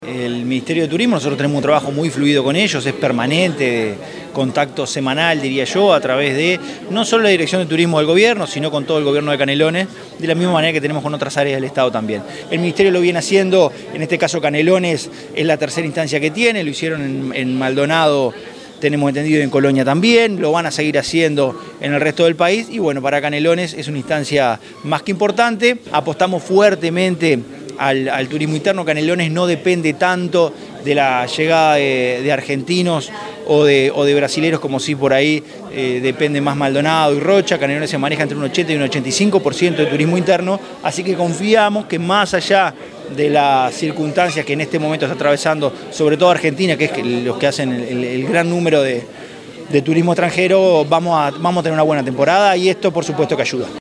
El Ministerio de Turismo y la Dirección de Desarrollo Turístico de la Intendencia de Canelones brindaron este miércoles una conferencia de prensa al respecto, en la que el Secretario General de la Intendencia, Dr. Esc. Francisco Legnani, calificó el procedimiento de registro como “una medida que ayuda a ordenar” el servicio turístico en Canelones.
francisco_legnani_-_secretario_general_0.mp3